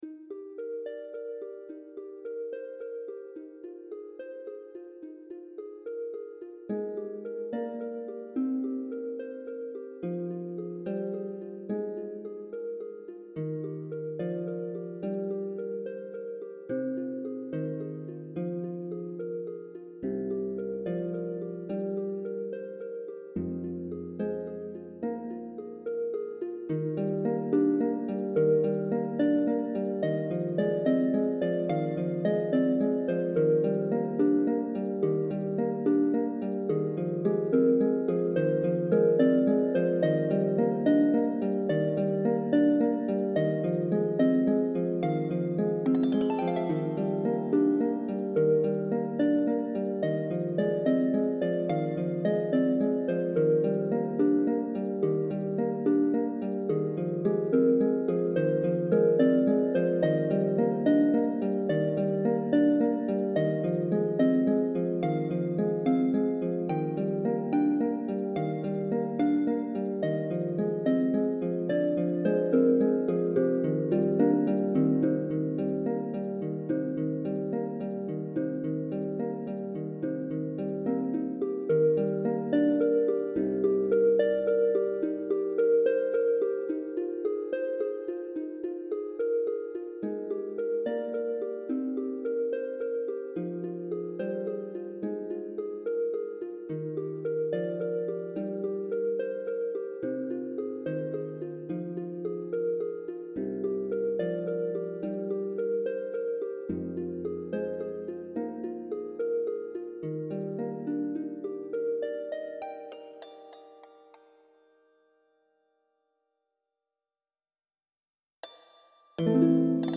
for intermediate lever or pedal harpists